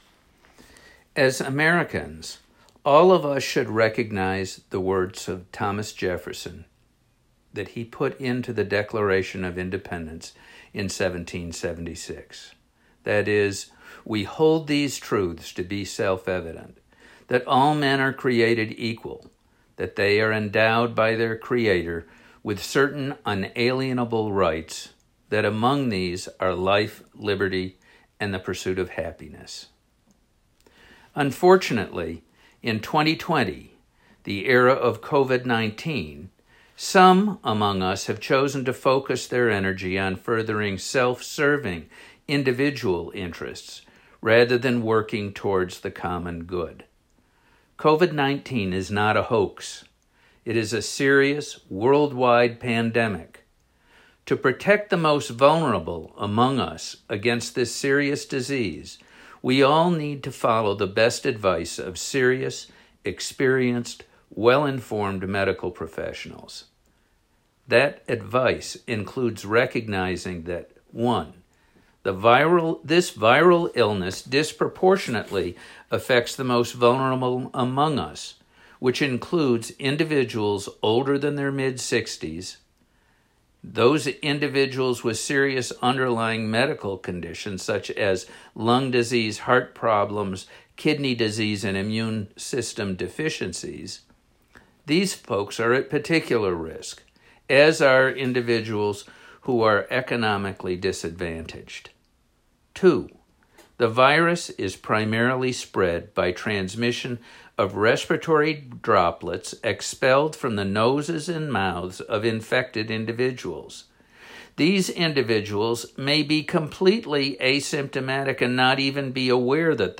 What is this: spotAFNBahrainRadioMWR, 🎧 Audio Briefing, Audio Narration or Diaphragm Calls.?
Audio Narration